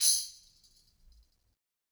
Tamb1-Hit_v1_rr1_Sum.wav